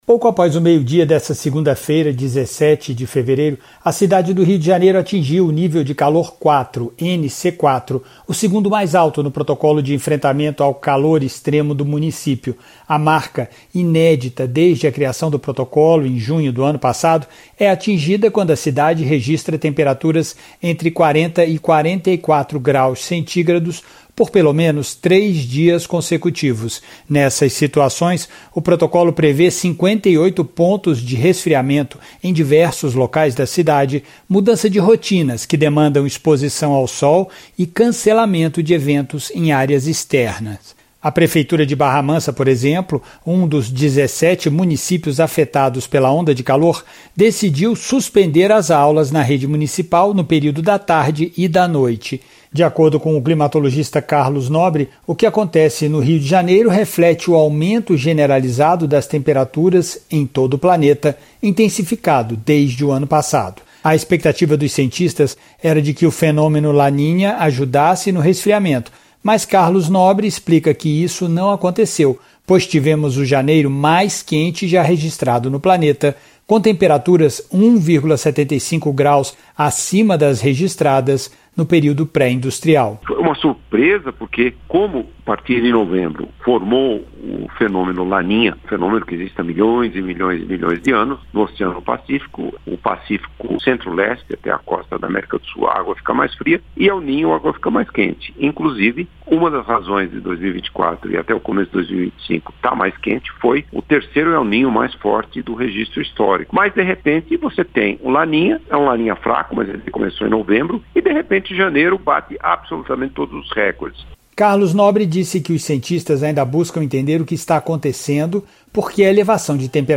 Inédita, a marca foi atingida com o registro de temperaturas entre 40 e 44 graus centígrados por três dias consecutivos. O climatologista Carlos Nobre explica que o fenômeno é reflexo da elevação das temperaturas em todo o planeta, mas a expectativa era de que o aumento perdesse força no início deste ano, com a chegada do La Niña, que resfria as águas do oceano Pacífico.